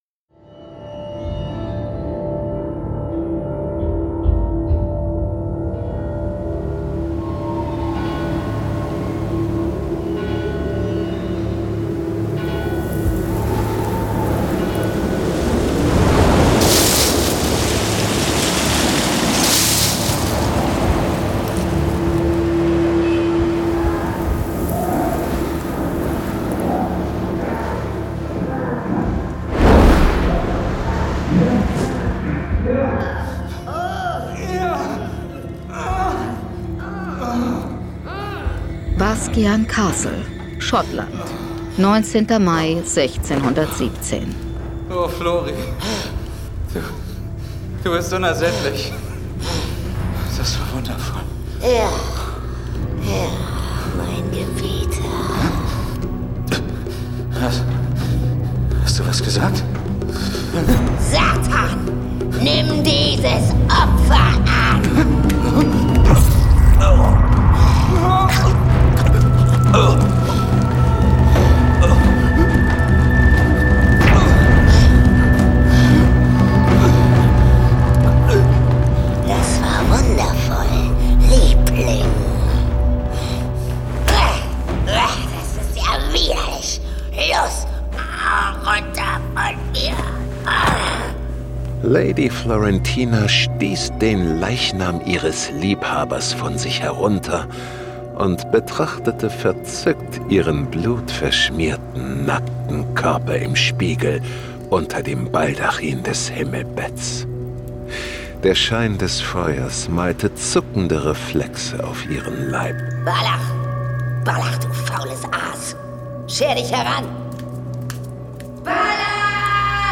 John Sinclair - Horror-Disco Sonderedition 18. Hörspiel.